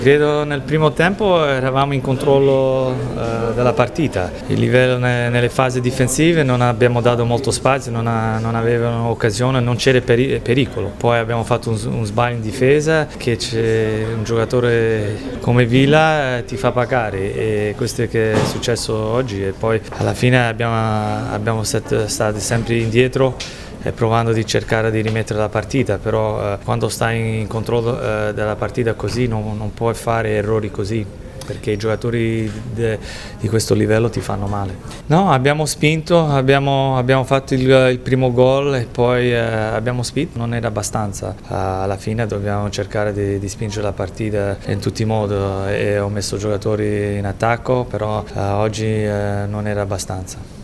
Le interviste del post-partita: